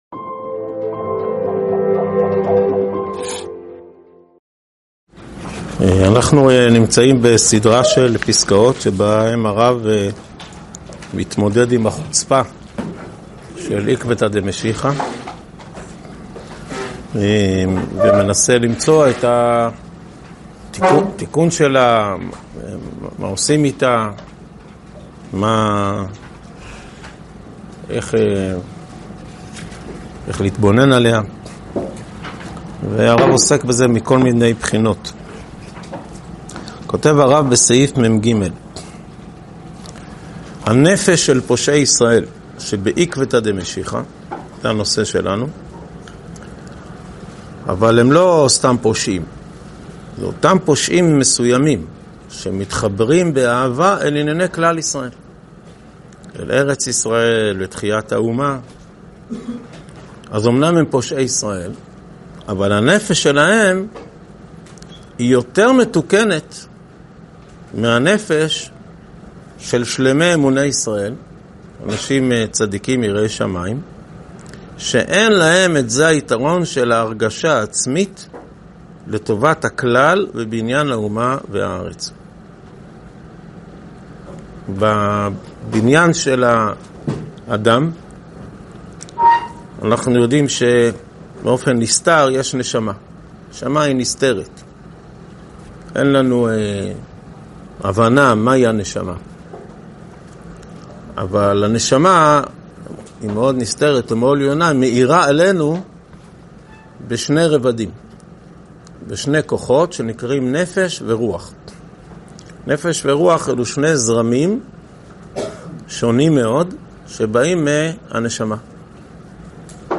הועבר בישיבת אלון מורה בשנת תשפ"ו.